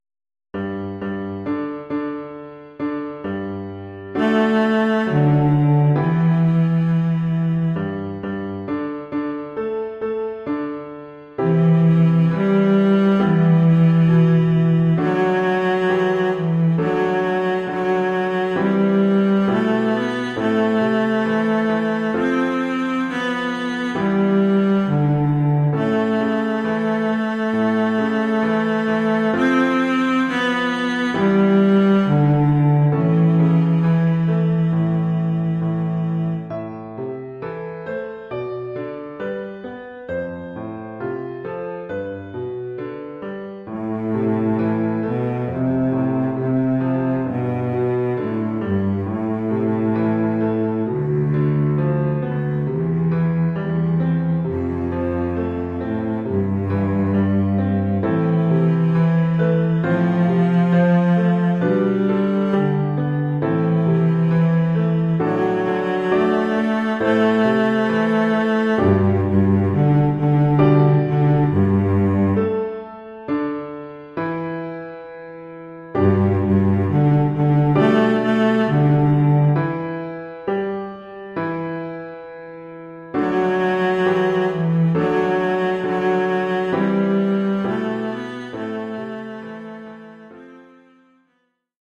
Oeuvre pour violoncelle et piano.
Niveau : débutant.